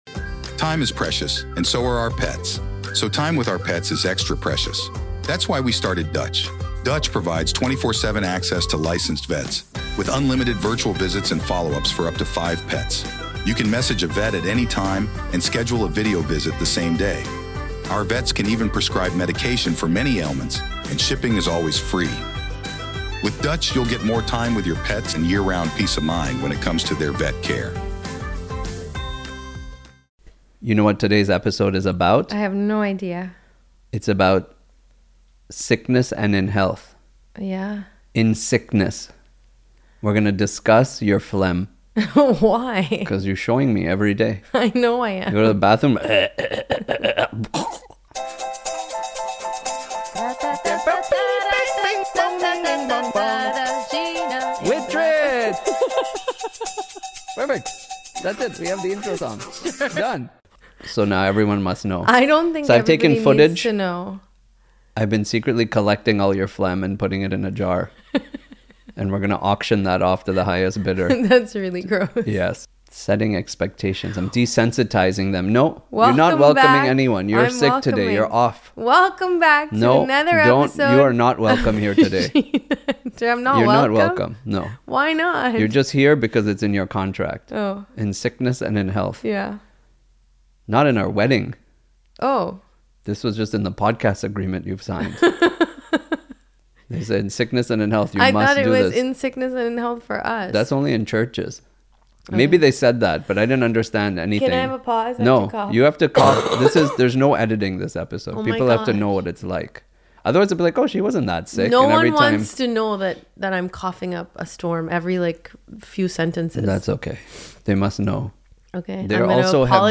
I recorded this one sick, unedited, and maybe slightly delirious.
Basically, the most chaotic sick day podcast you’ll ever hear. If you can make it through the coughs, you’ll laugh just as hard as we did (and maybe question our marriage vows in sickness and in health).